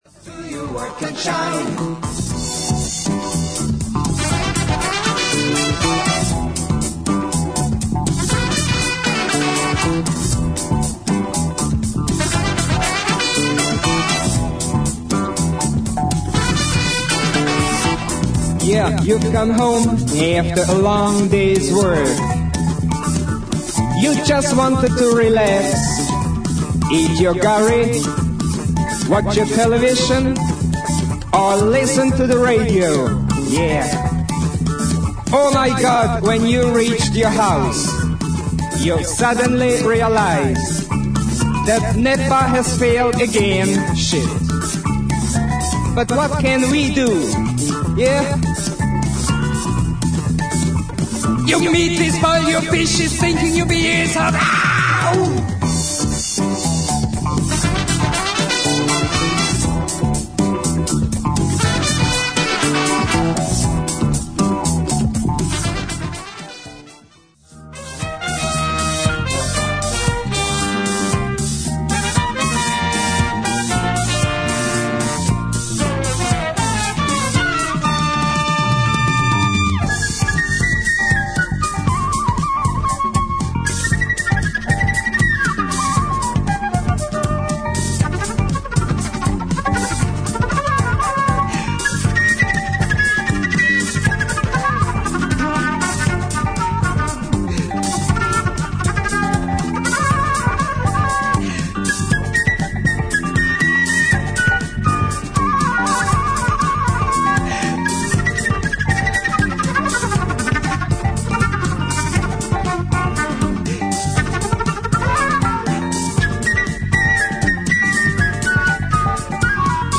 中盤に出てくるスペーシーなシンセ・ワークも格好良いアフロ・ブギー
ガラージ・ライクな哀愁系アフロ・ディスコ・ナンバー
清涼感のある軽快なグルーヴが気持ち良い